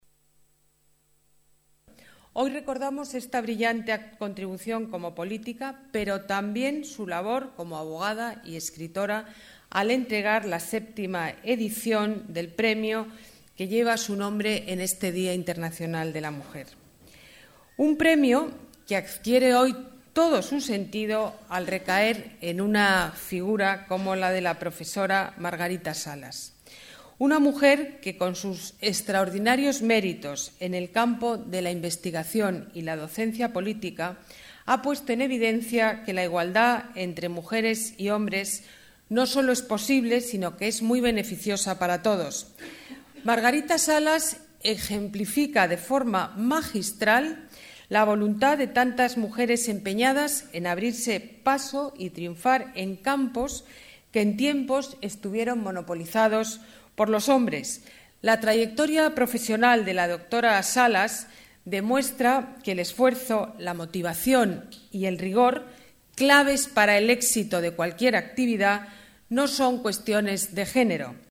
La alcaldesa, Ana Botella, entrega el galardón a la científica en el acto institucional del Día Internacional de la Mujer